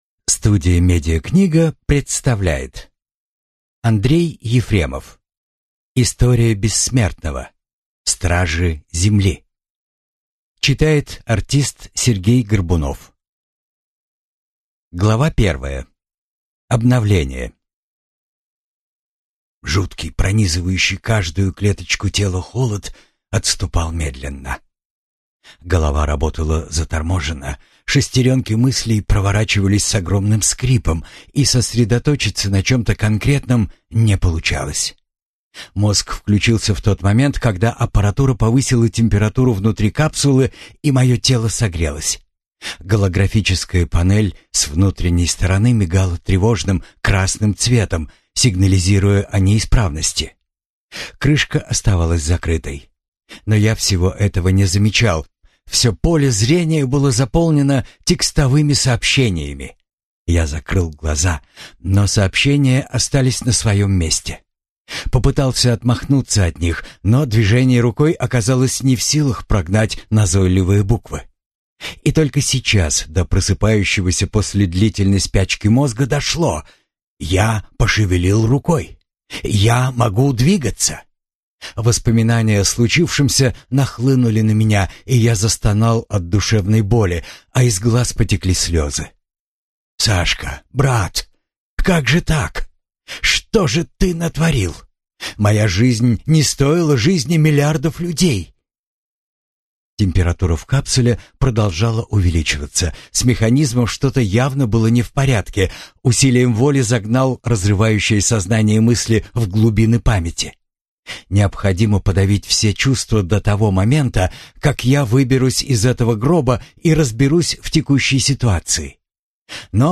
Аудиокнига История Бессмертного. Книга 6. Стражи Земли | Библиотека аудиокниг